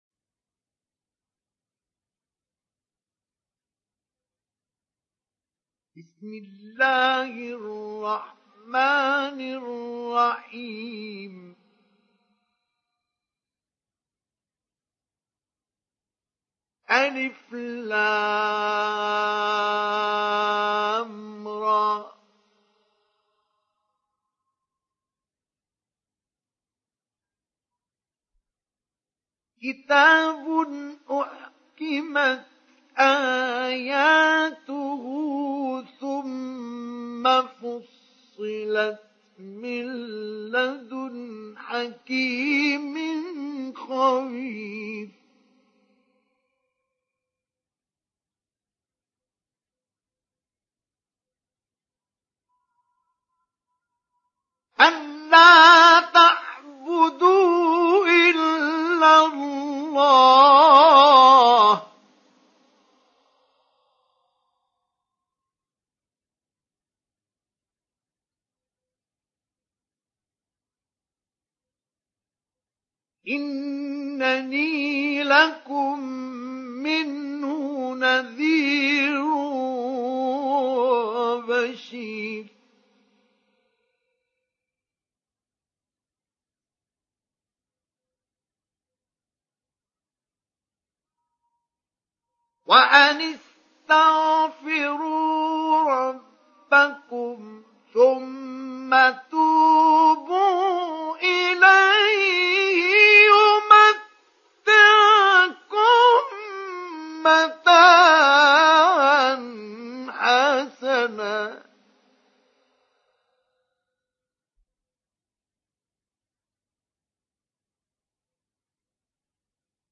Download Surat Hud Mustafa Ismail Mujawwad